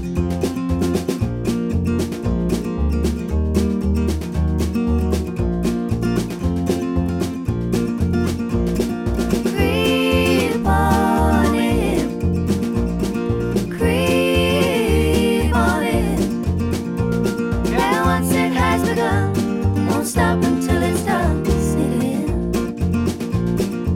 For Duet Easy Listening 2:59 Buy £1.50